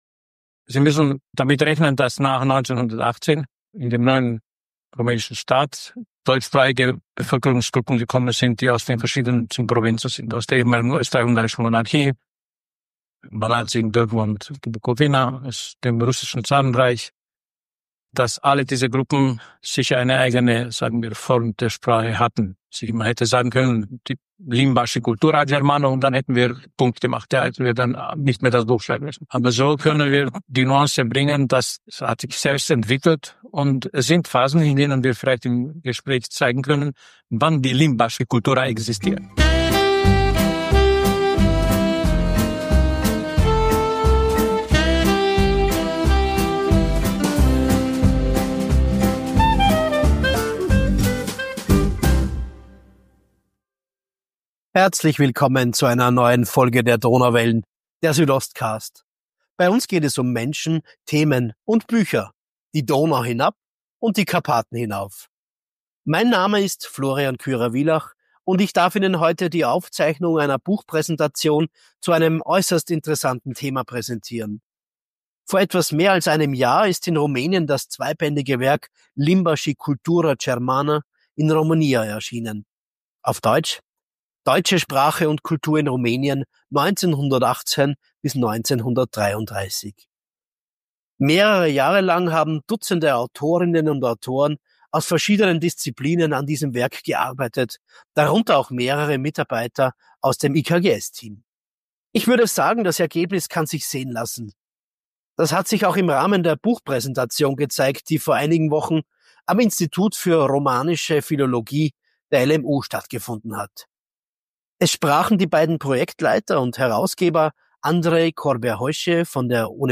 #28 Buchpräsentation. Deutsche Sprache und Kultur in Rumänien 1918–1933 ~ Donauwellen. Der Südostcast Podcast